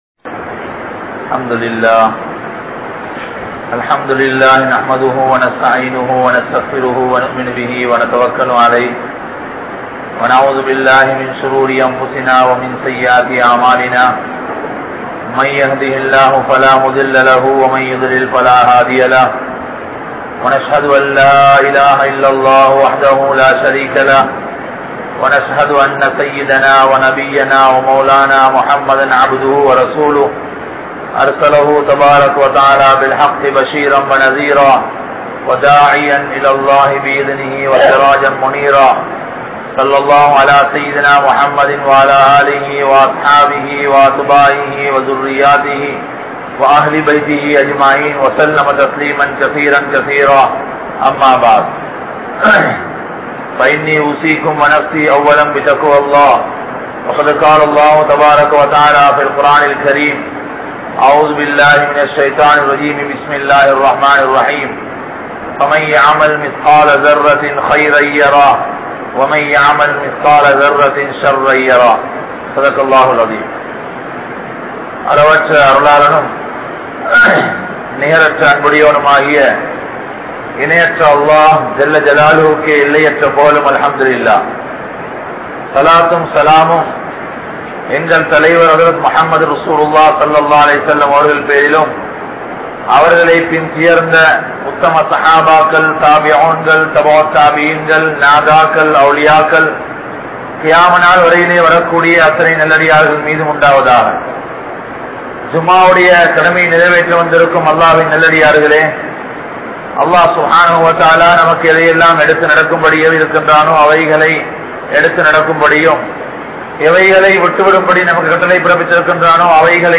Paavaththitku Kaaranam Koorufavarhal (பாவத்திற்கு காரணம் கூறுபவர்கள்) | Audio Bayans | All Ceylon Muslim Youth Community | Addalaichenai
Dehiwela, Junction Jumua Masjith